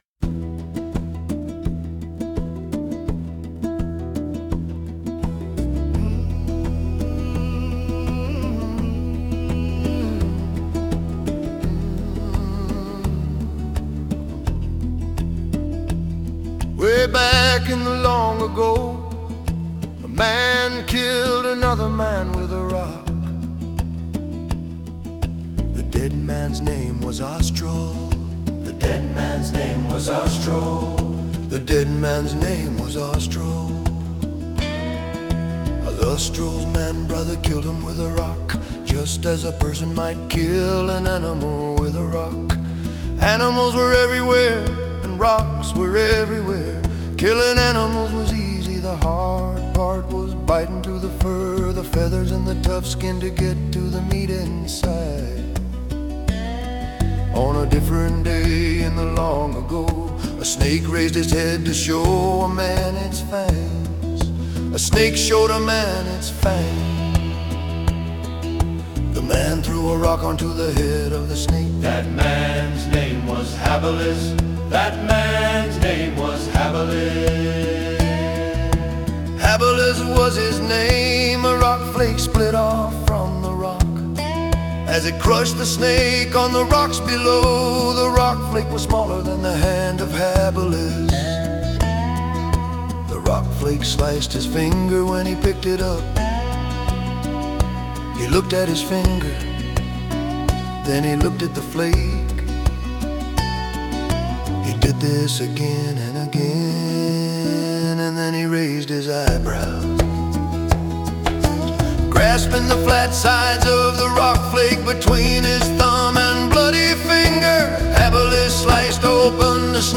“Way Back in the Long Ago” is a campfire story told by a group of old men.